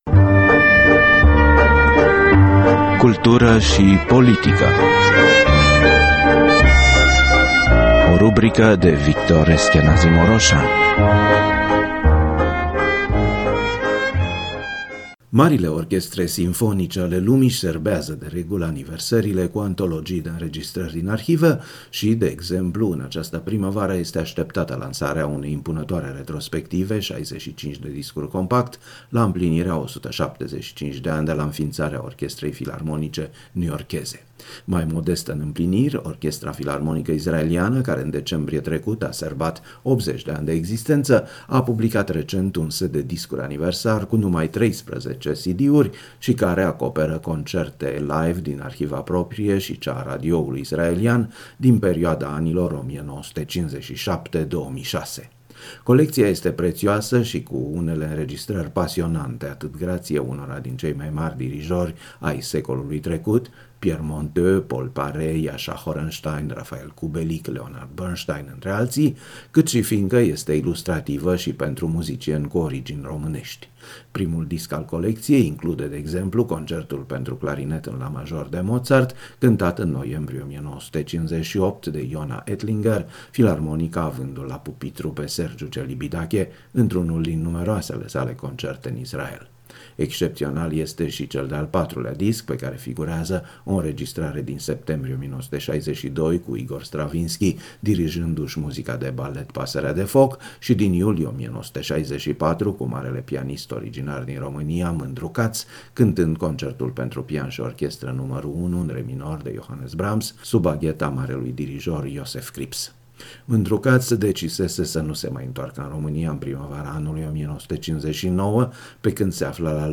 O antologie de înregistrări live la a 80-a aniversare a Filarmonicii Israelului.
[Mîndru Katz la pian, extras din Concertul pentru pian și orchestră No. 1 în re minor de Johannes Brahms]